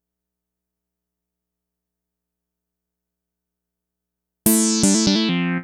Synth 04.wav